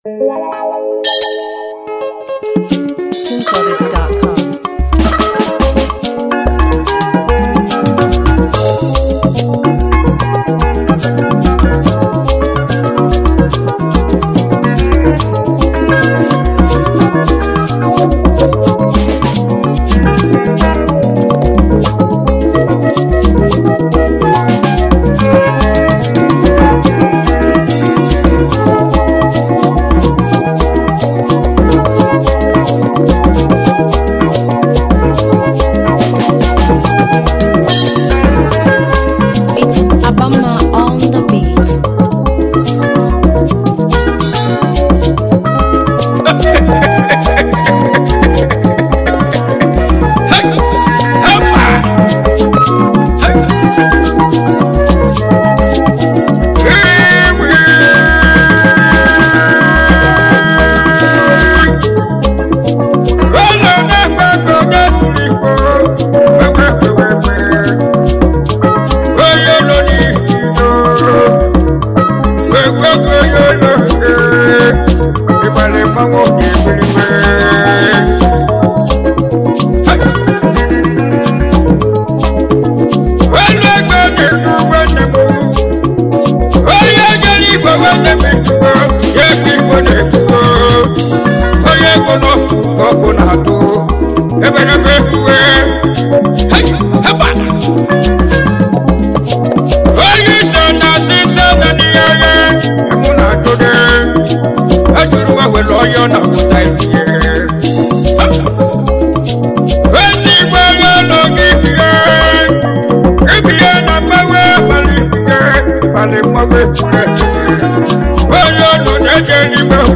Super talented Nigerian Highlife singer